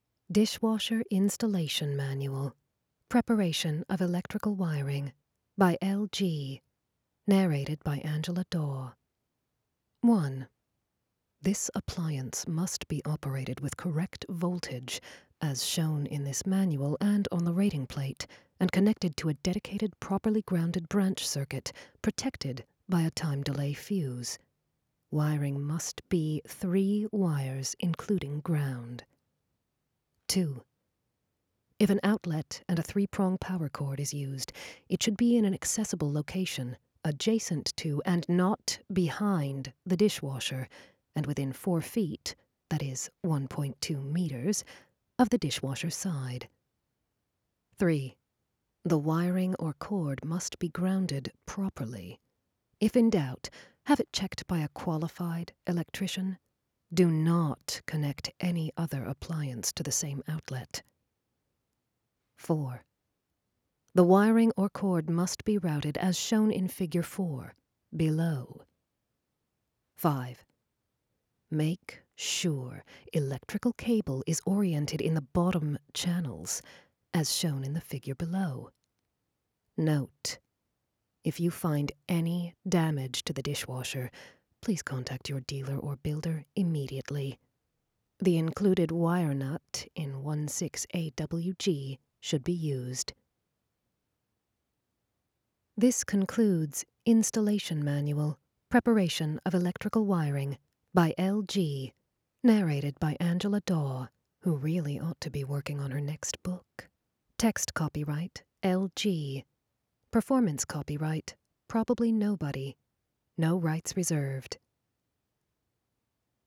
Sultry and hilarious.
The ravishment of wiring instructions, firmly delivered.